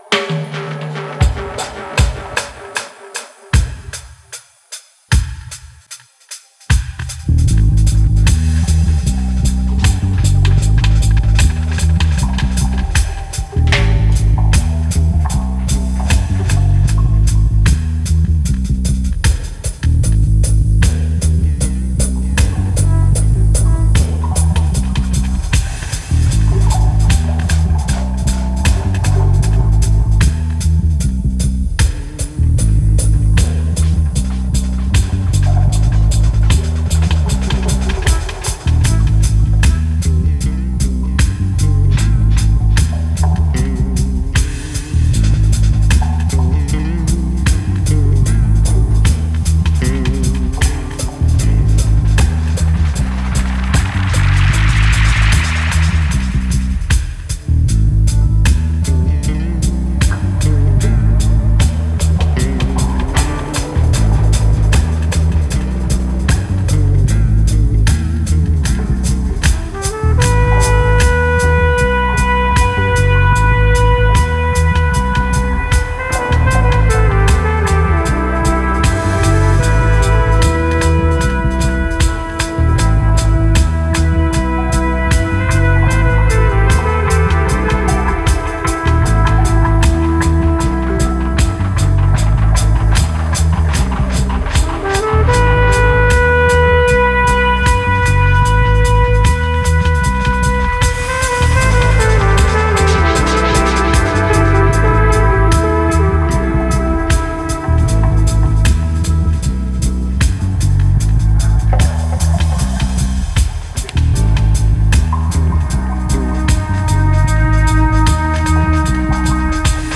Genre: Dub, Psy-Dub.